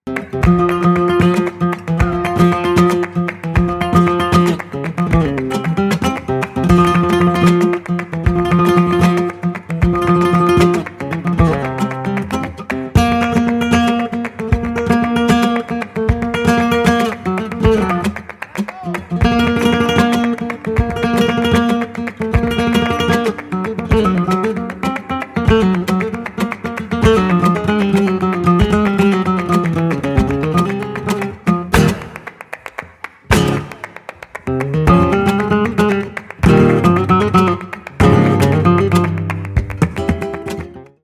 Bulerías